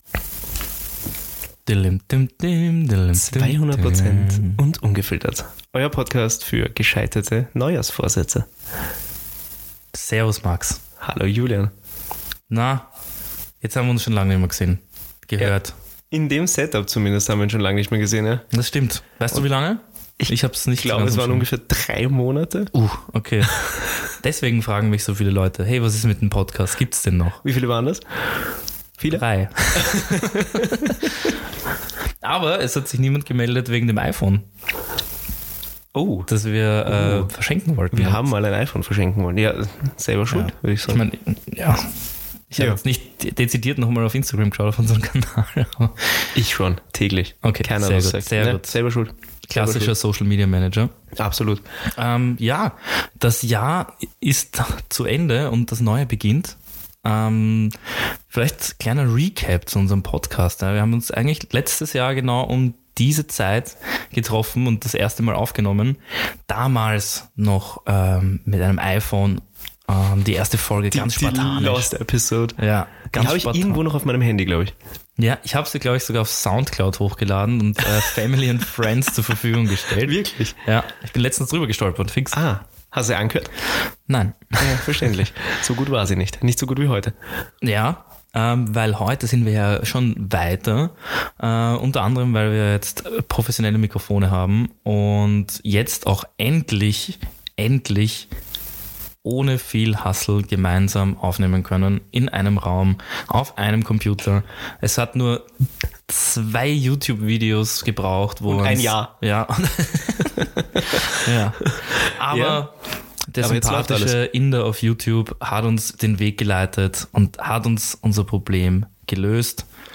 Der Sound? Klarer!